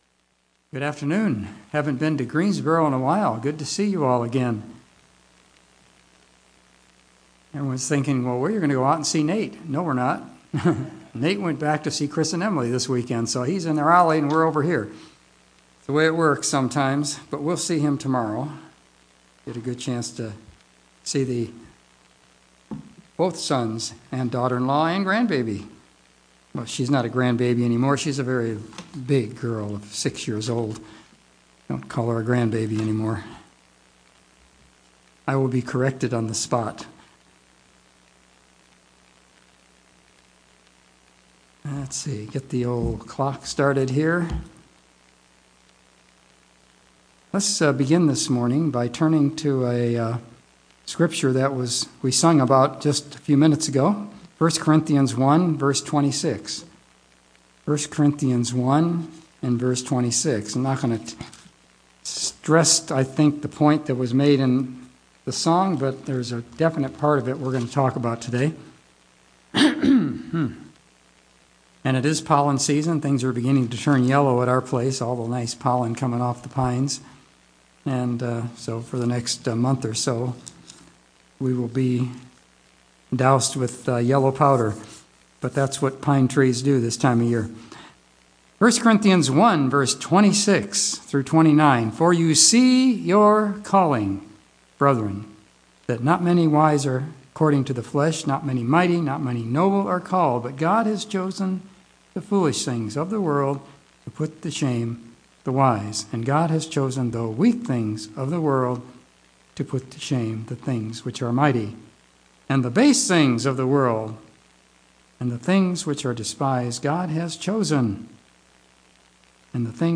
A sermon about the nature of the calling we have from God our Father.